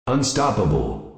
Unstoppable.wav